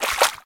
Footsteps_Water_1.ogg